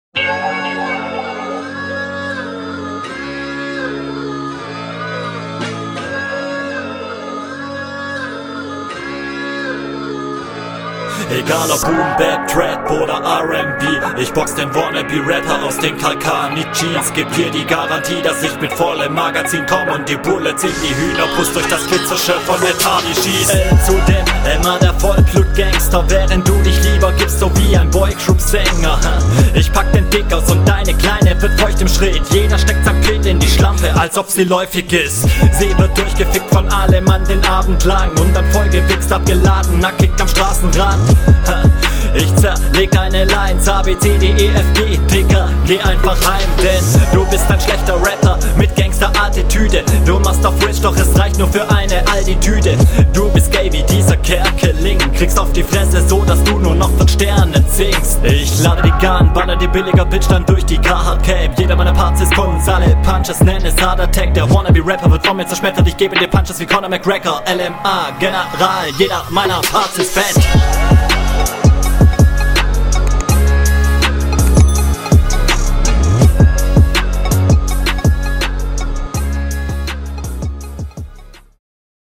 Stimmlich hast du hier gut performet.
Beat wieder nice, Stimme find auch nice.